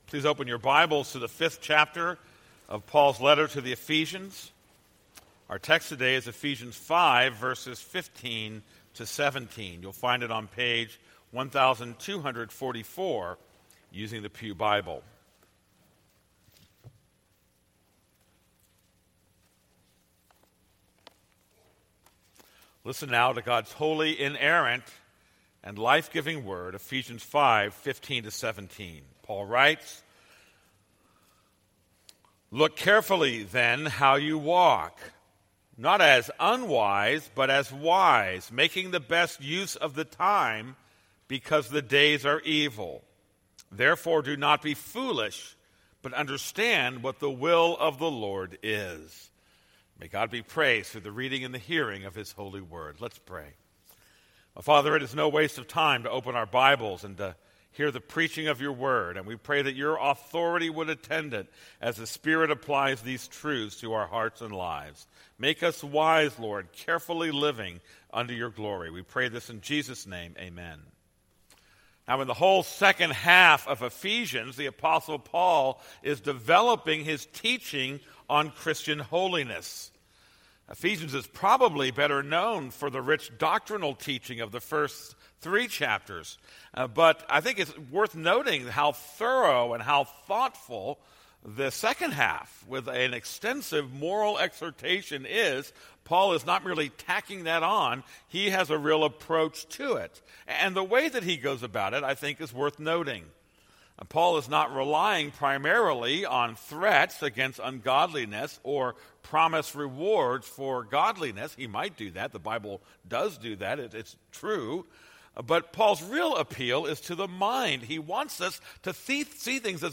This is a sermon on Ephesians 5:15-17.